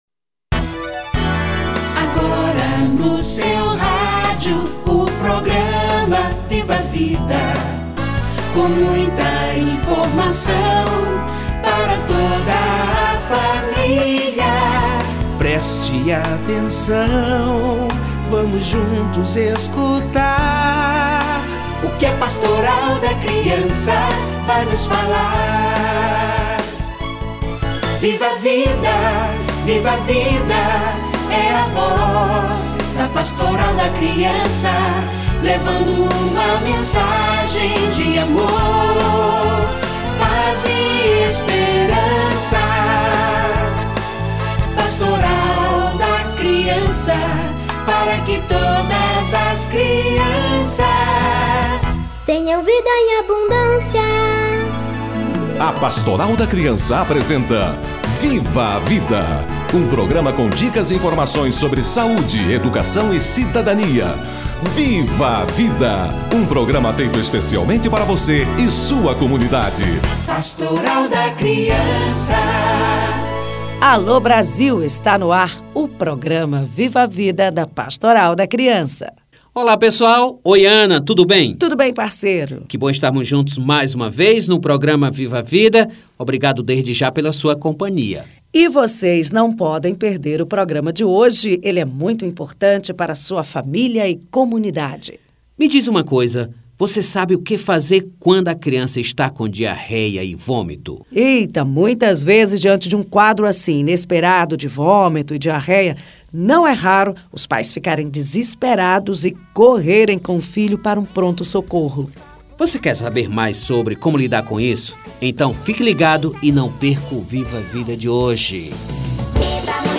Vômitos e diarreias - Entrevista